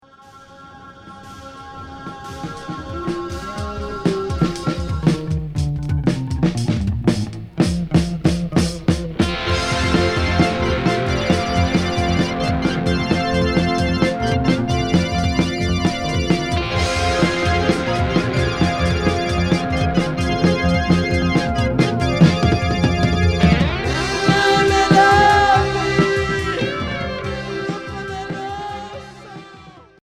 Progressif psychédélique Unique 45t retour à l'accueil